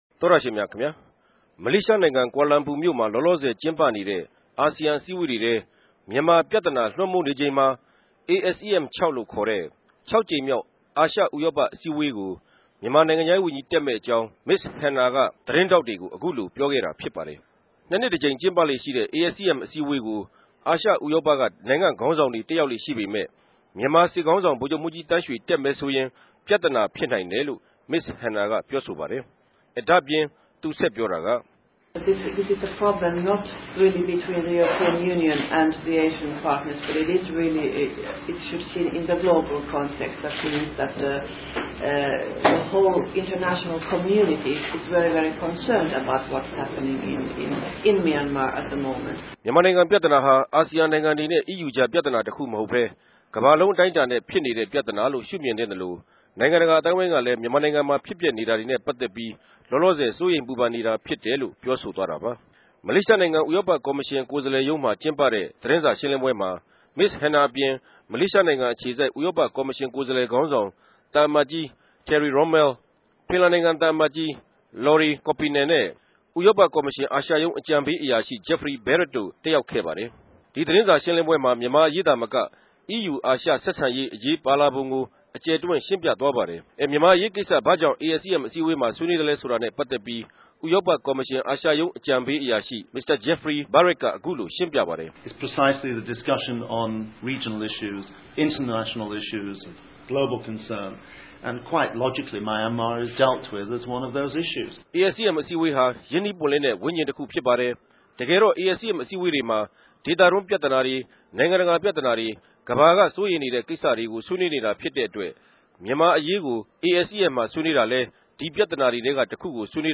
ကြာလာလမ်ပူ္ဘမိြႚကနေ သတင်းပေးပိုႛထားတာကို နားထောငိံိုင်ပၝတယ်။